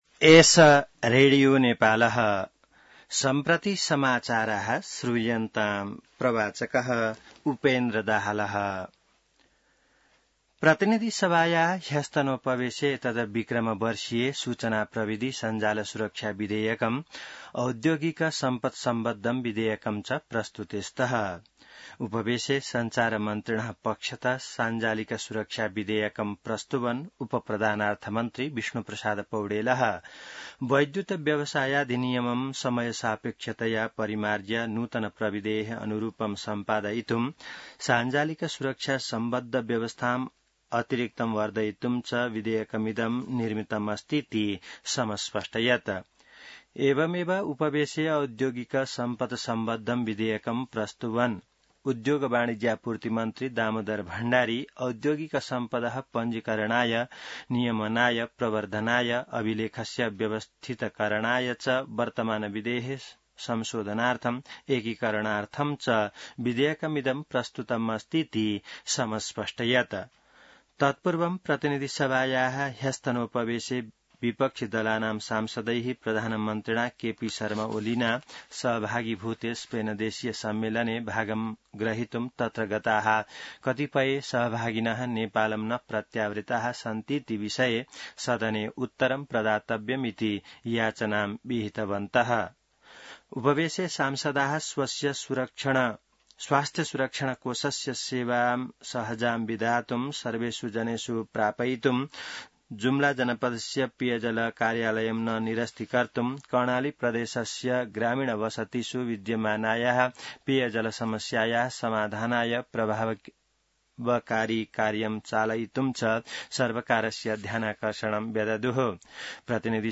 संस्कृत समाचार : ३० असार , २०८२